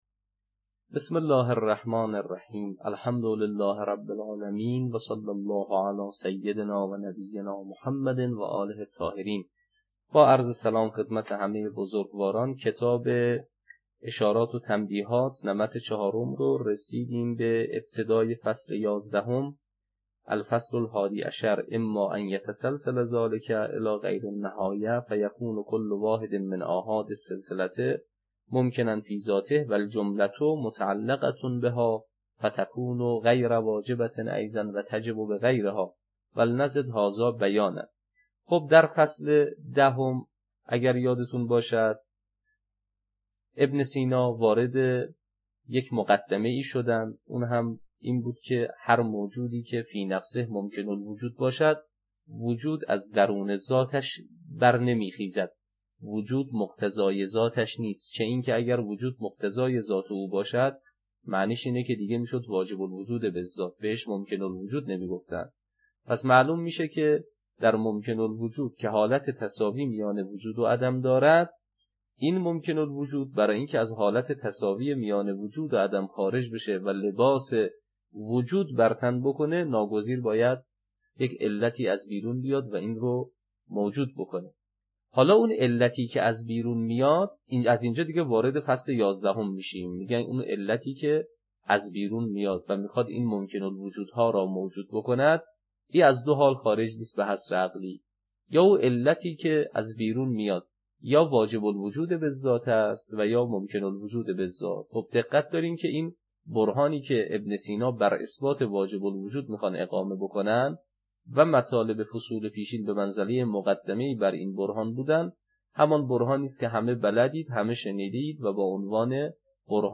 شرح اشارات و تنبیهات، تدریس